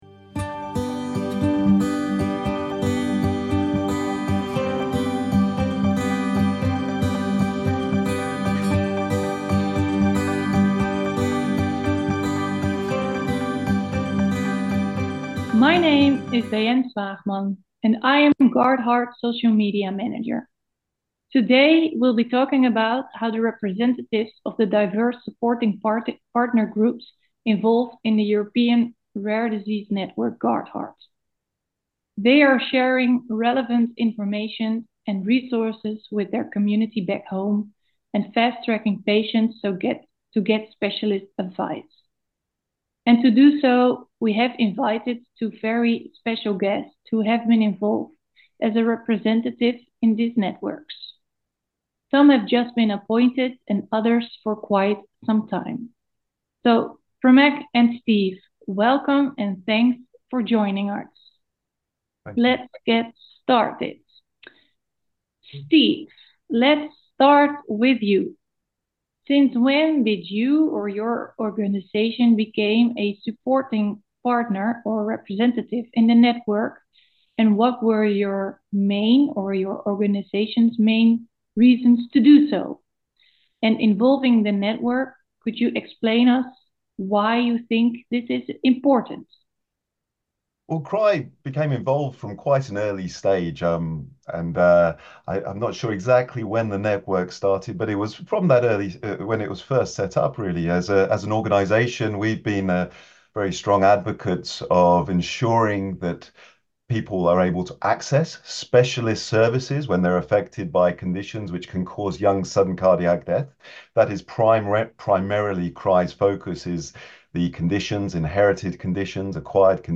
In March 2024 ERN GUARDHEART started with a podcast series in which all ePags or patient representatives are requested to participate. The idea is that in each recording, we are interviewing two representatives as a duo.